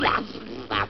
Звуки тасманийского дьявола: звук плевка тасманского дьявола из мультфильма